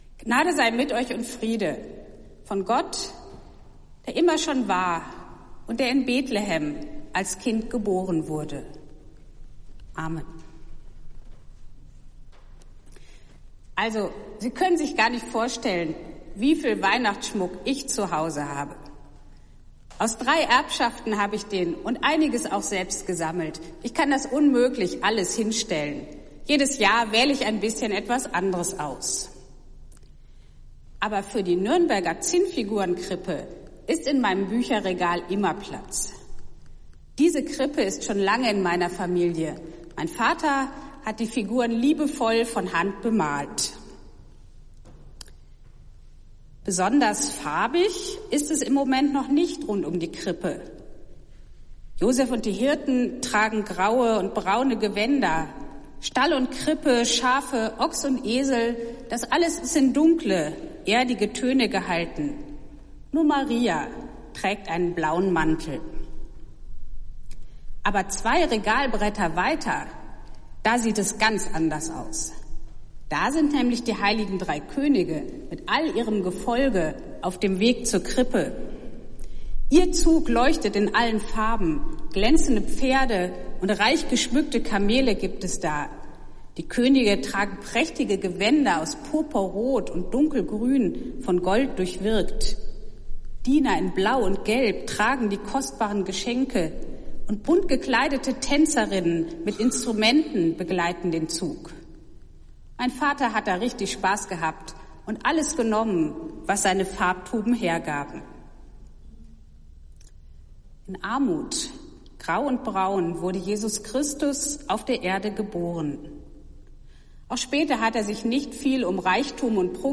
Predigt des Gottesdienstes aus der Zionskirche vom Dienstag, dem zweiten Weihnachtstag